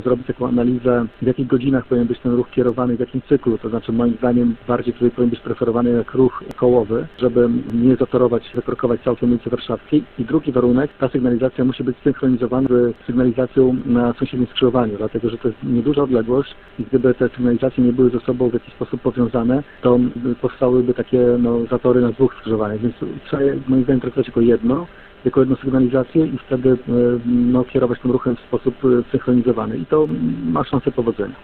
Burmistrz Iwaszkiewicz dodaje, że sygnalizacja powinna być zsynchronizowana z tą na pobliskim skrzyżowaniu.